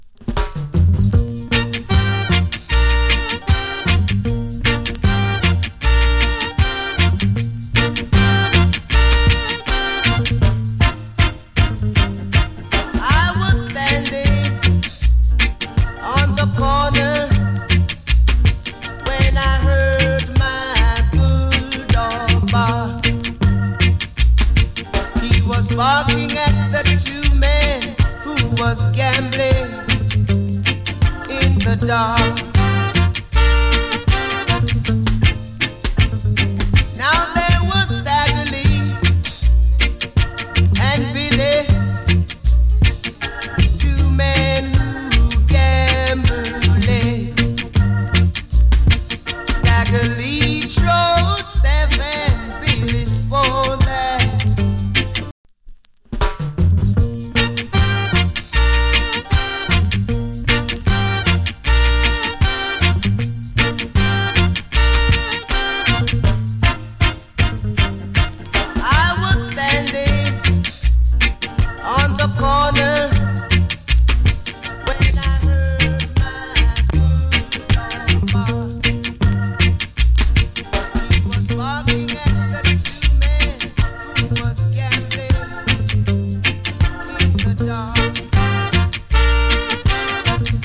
NICE REGGAE VOCAL !